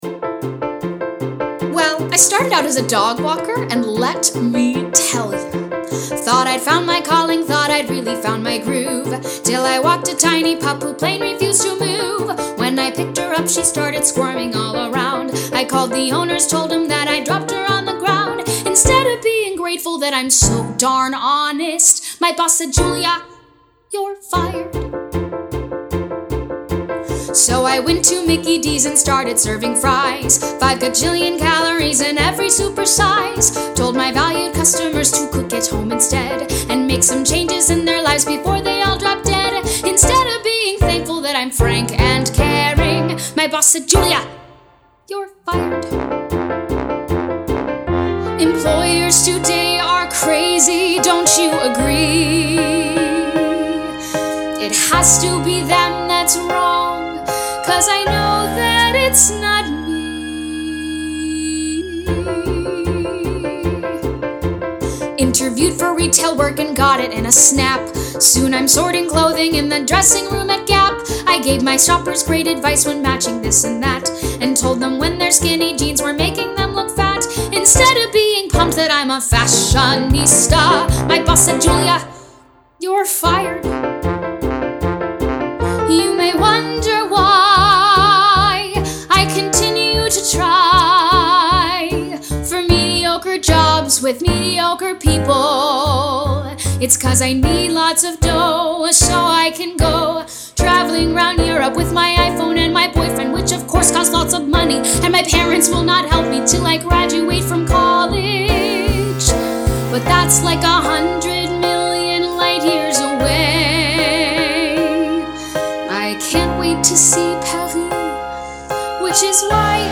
(A3-C5)
Vocal Demo